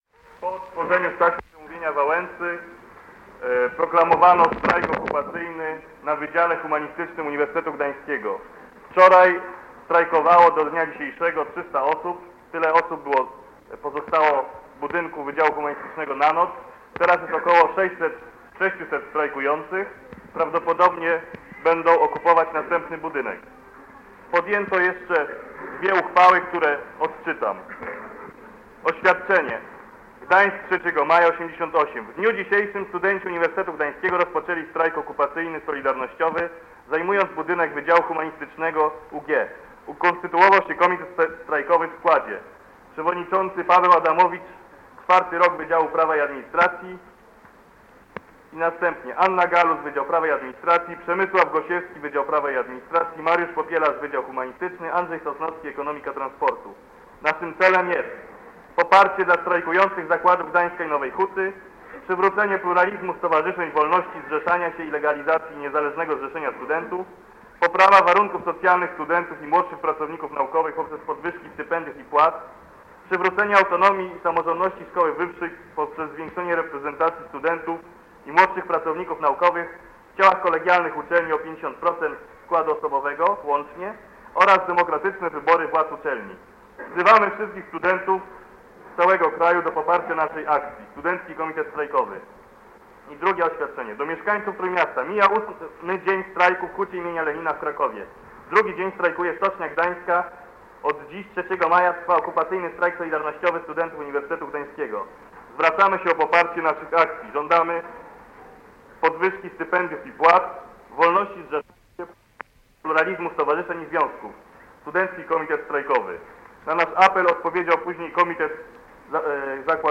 Strajk solidarnościowy studentów w UG: relacja [dokument dźwiękowy] - ${res.getProperty('base.library.full')}